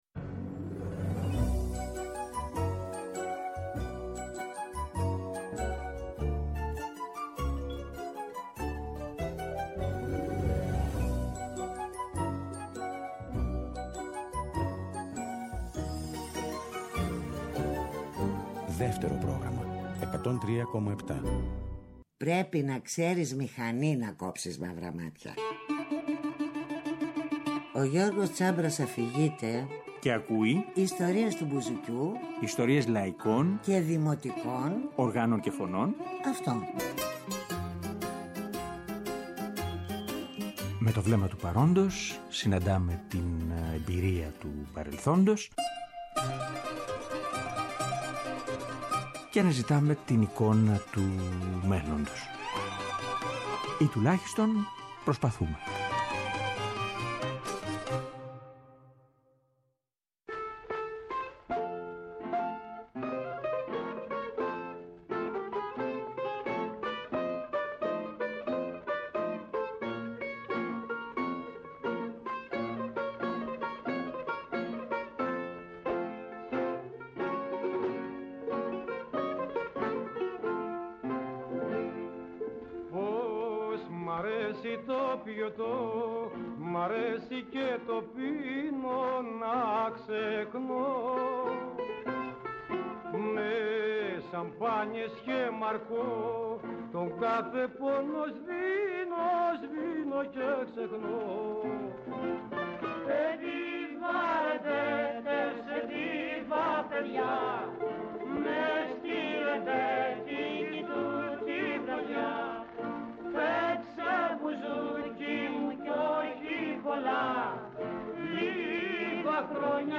Στην πρώτη εκπομπή ακούμε επιλογές του και συζητάμε μαζί του για τις εμπειρίες του από την δημιουργία και την κυκλοφορία των πέντε βιβλίων του.